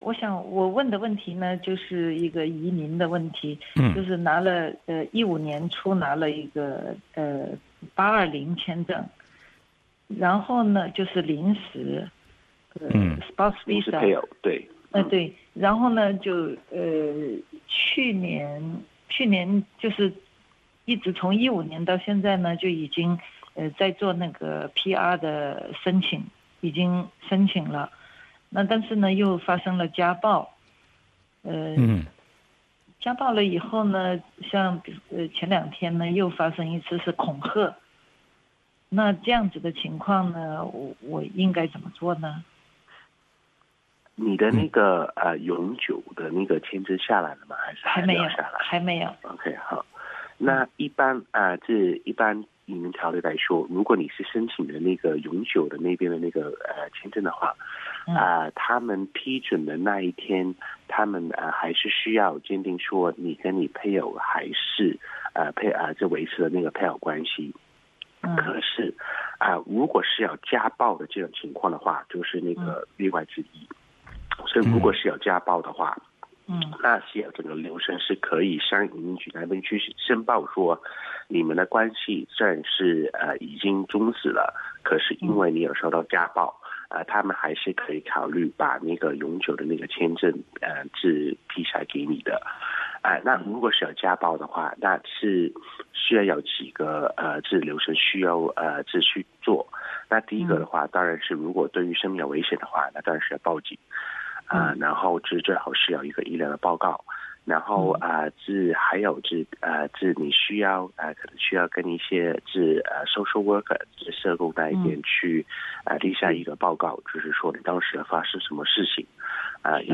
SBS普通话《现场说法》节目嘉宾律师介绍称，移民局审核移民签证申请时会看申请人是否还在婚姻状态，不过如果遭遇家暴的话，情况有所不同，移民局仍然会考虑批准签证。